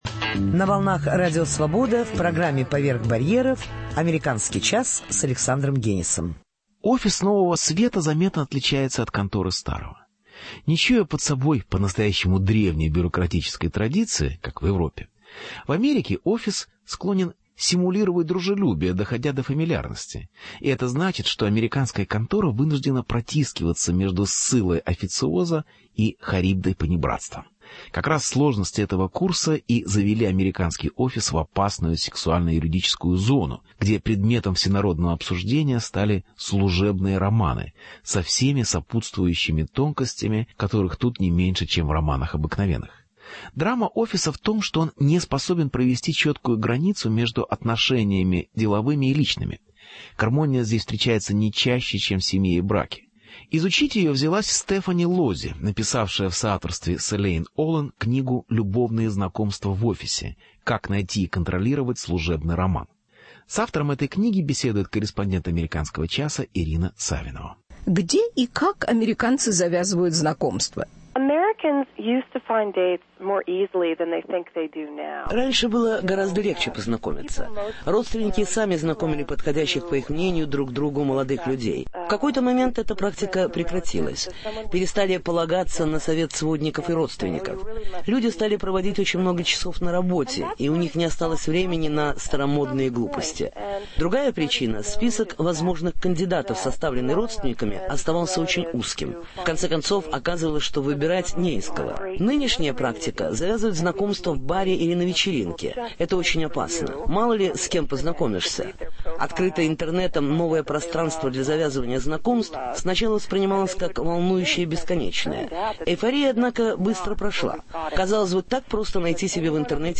Интервью. Служебный роман: этикет любовных отношений на работе.